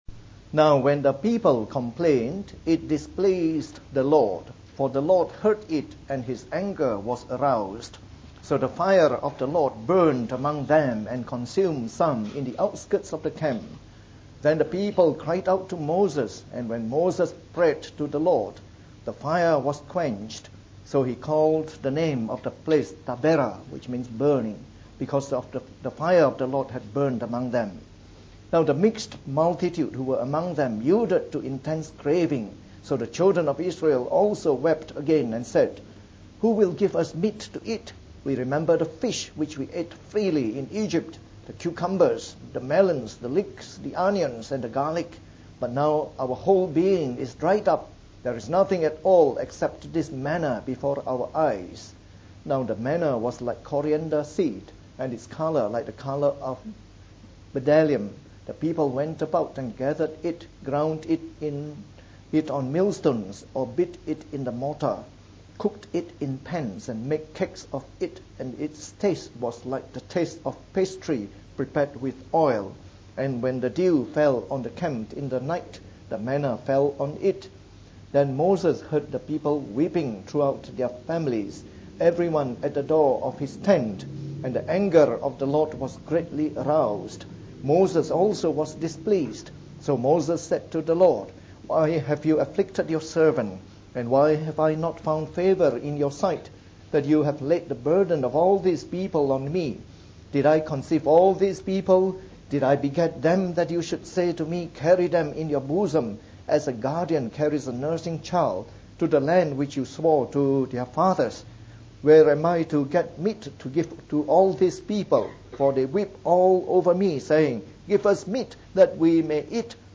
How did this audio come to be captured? Preached on the 23rd of June 2013. From our series on the “Book of Numbers” delivered in the Morning Service.